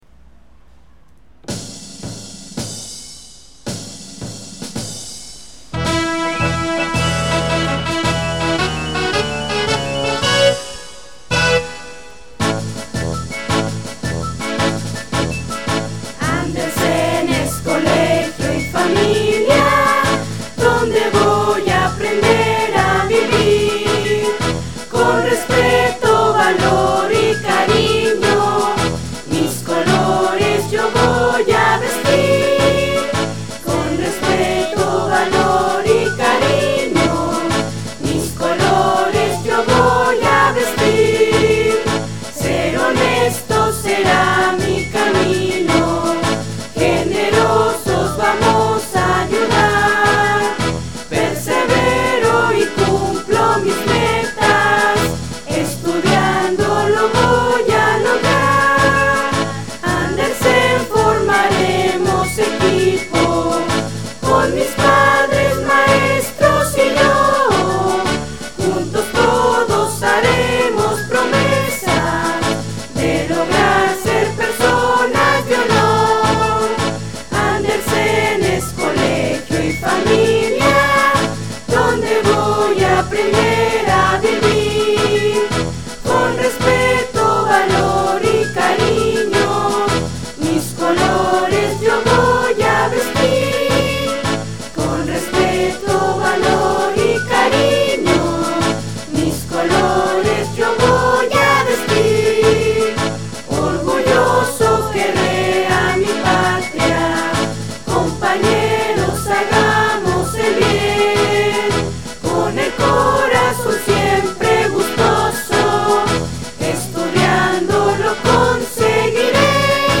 Himno del Colegio
himno-andersen-audio-1.mp3